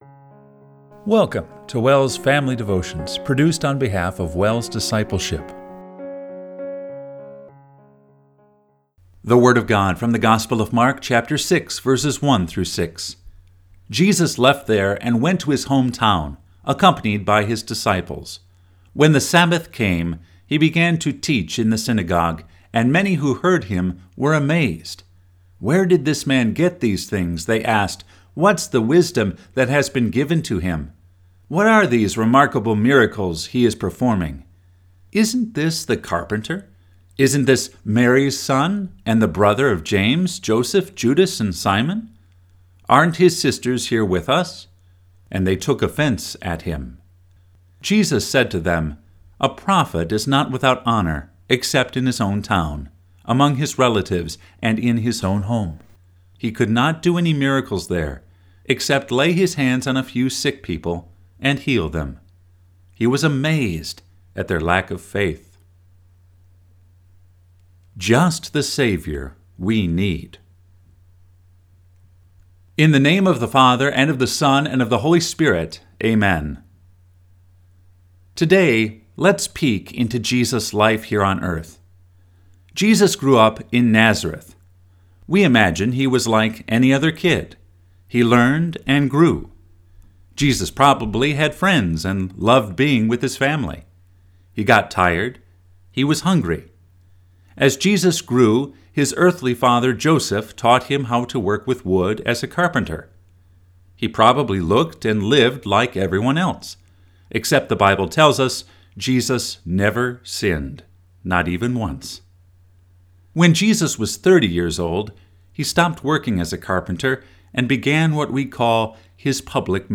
Family Devotion – July 12, 2024